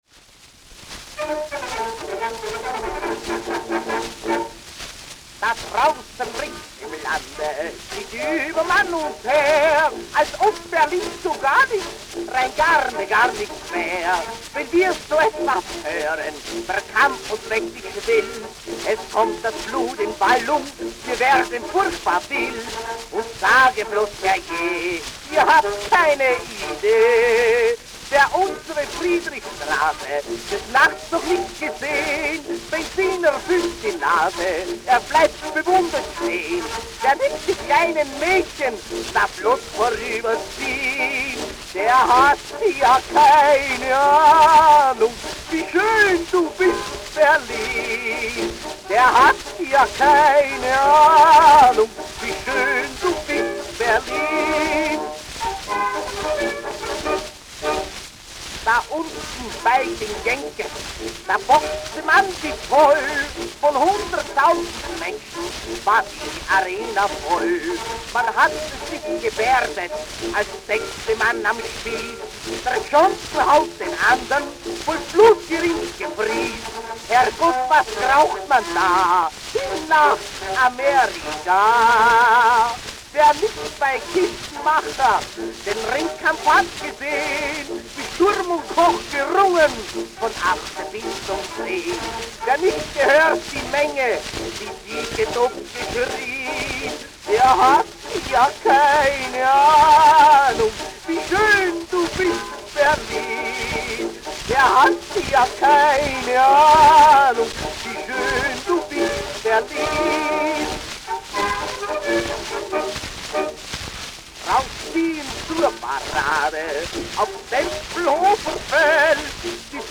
mit Orchesterbegleitung